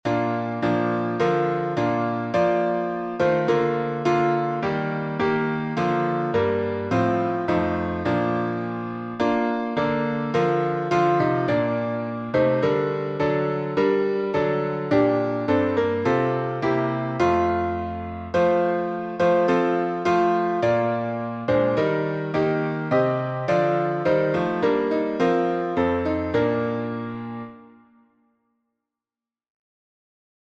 Words by W. Sanders Tune: W. S by Henry Smart Key signature: B flat major (2 flats) Time s